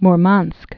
(mr-mänsk, mrmənsk)